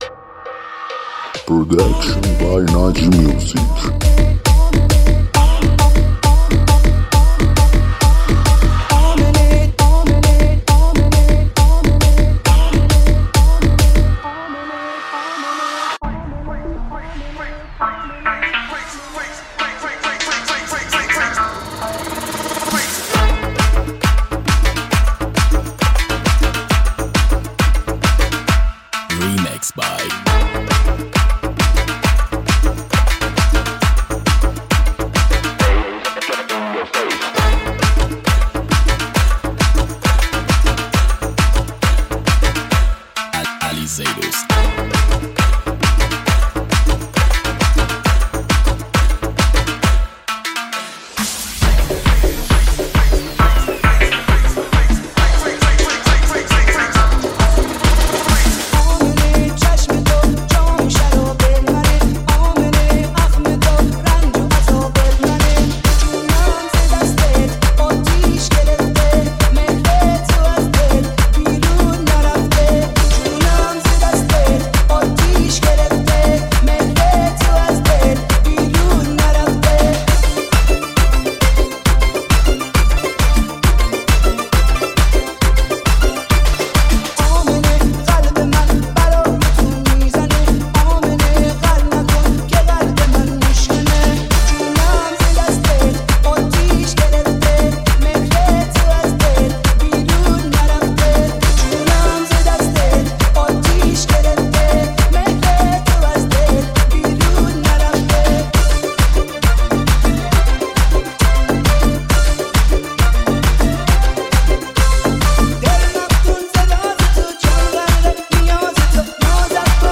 ریمیکس شاد بندری